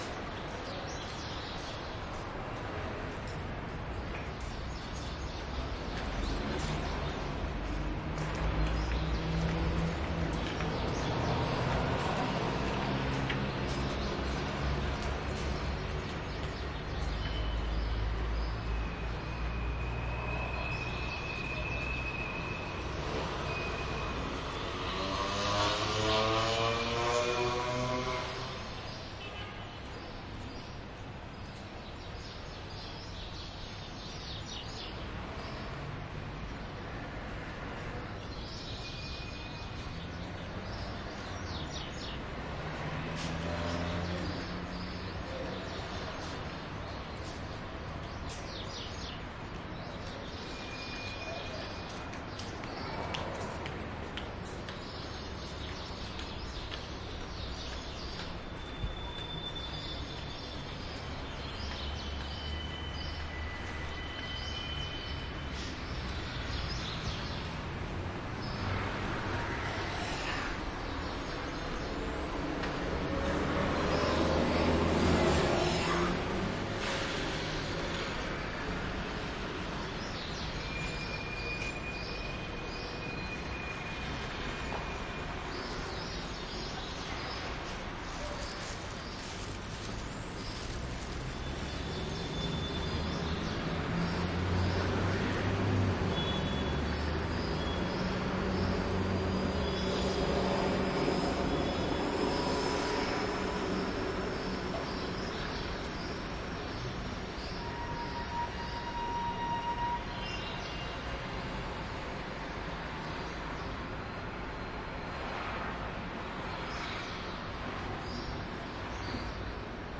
摩洛哥 " 街道 中型交通 密集的响亮的喇叭声 回响着轻便摩托车的活动 卡萨布兰卡，摩洛哥 MS
描述：街道中等交通密集响亮的喇叭回声轻便摩托车活动卡萨布兰卡，摩洛哥MS.wav
标签： 有声 喇叭 交通 街道 摩洛哥 回声 轻便摩托车 活动 卡萨布兰卡
声道立体声